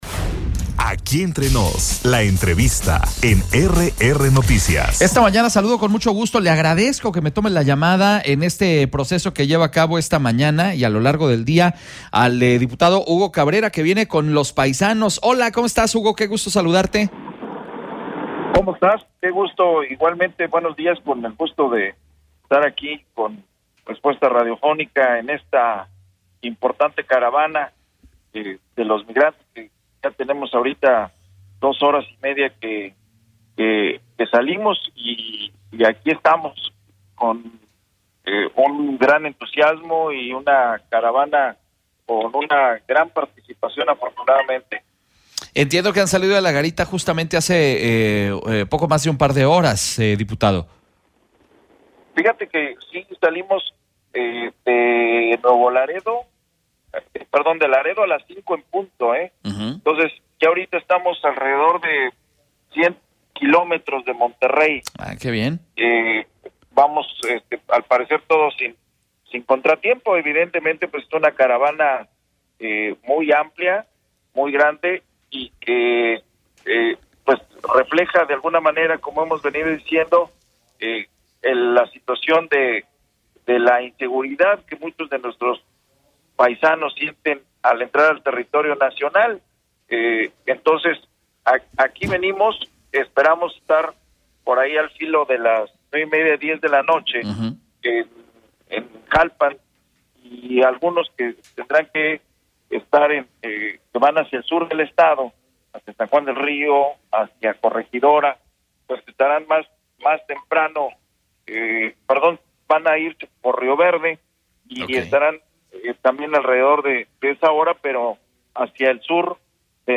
ENTREVISTA-DIPUTADO-HUGO-CABRERA.mp3